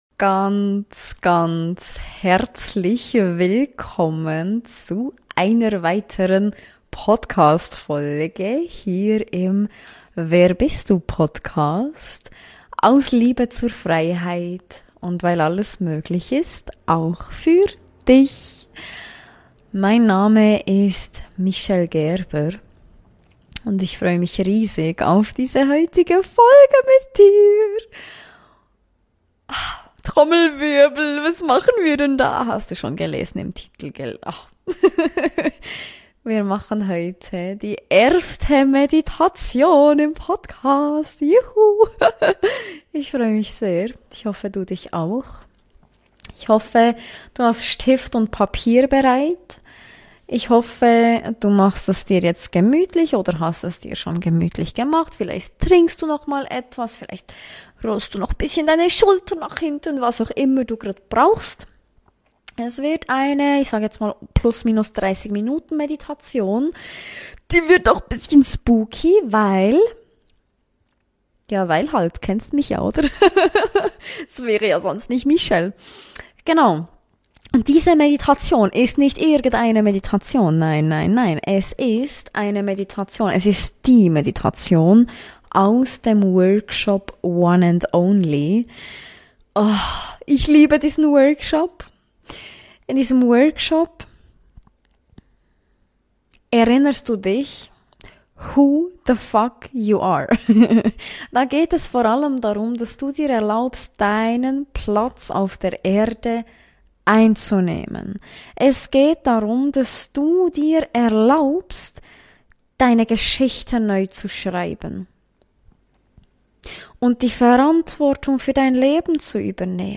Podcastfolge mit Dir teilen. die allererste Meditation. juhuuu' nicht irgendeine Meditation, nein.